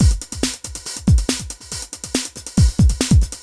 drums.wav